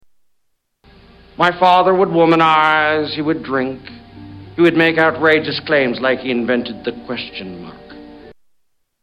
Category: Movies   Right: Personal